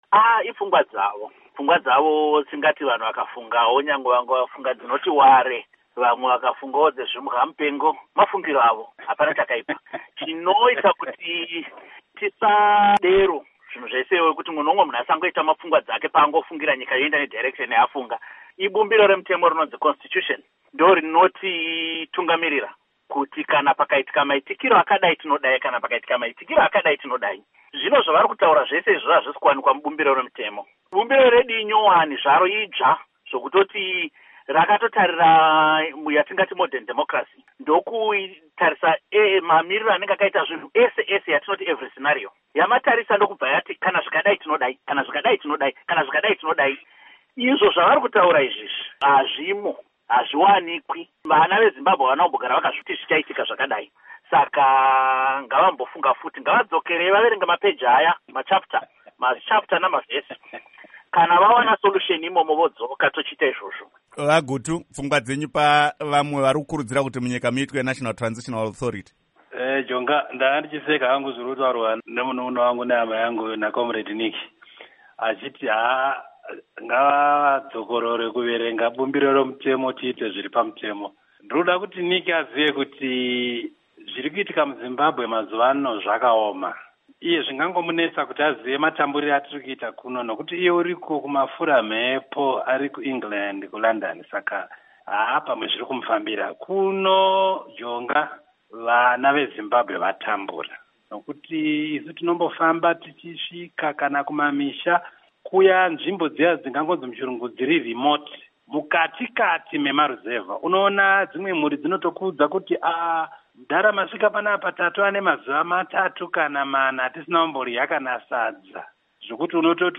Hurukuro naVaObert Gutu pamwe naVaNick Mangwana